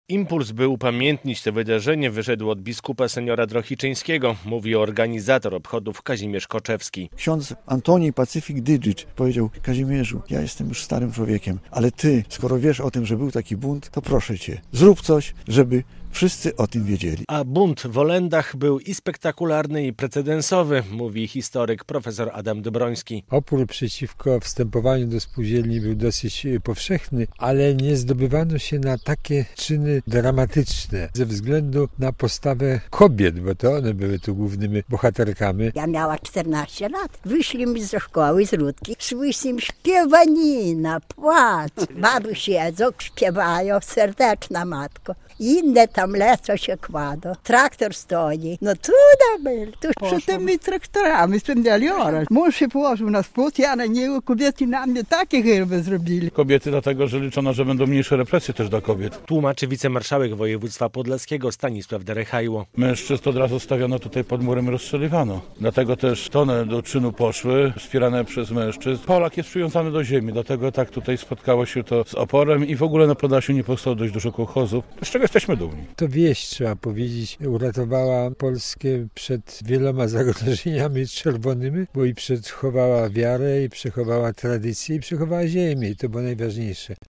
65. rocznica buntu mieszkańców wsi Olendy - relacja